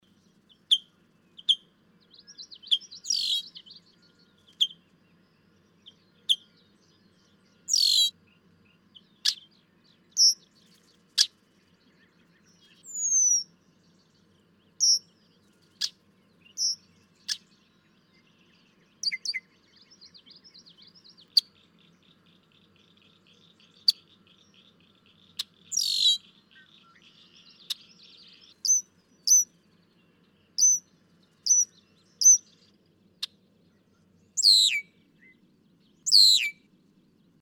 Red-winged blackbird
♫326. Illinois call dialect, calls excerpted from longer sequence of songs and calls. May 30, 2008. Levee Road, Neunert, Illinois. (0:37)
326_Red-winged_Blackbird.mp3